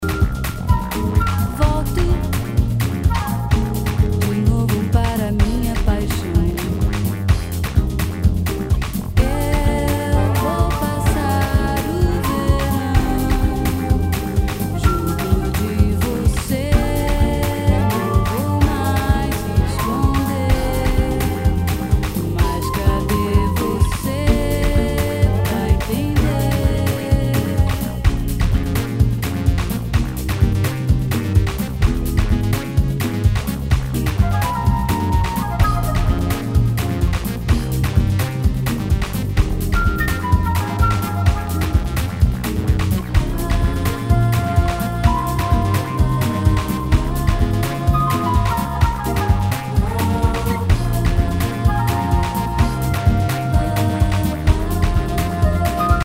HOUSE/TECHNO/ELECTRO
ナイス！ラテン・ヴォーカル・ハウス！